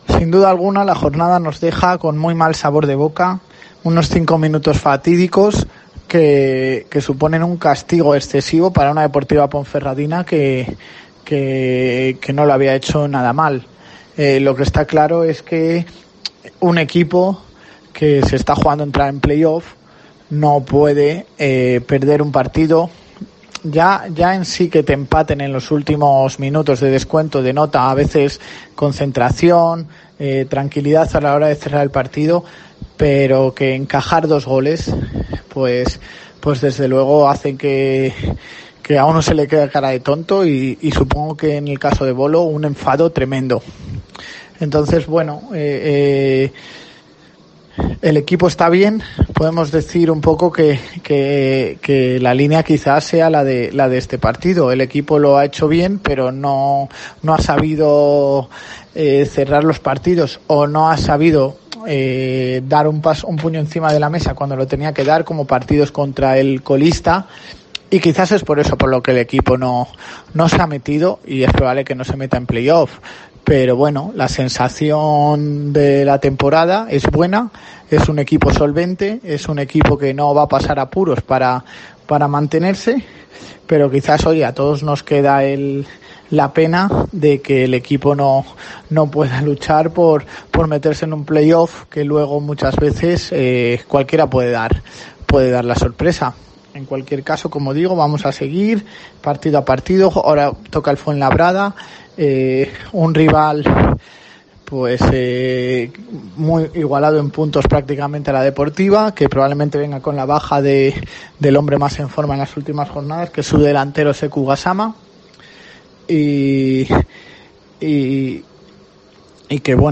TIEMPO DE OPINIÓN DCB